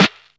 • Clear Acoustic Snare G Key 375.wav
Royality free snare sample tuned to the G note.
clear-acoustic-snare-g-key-375-mXw.wav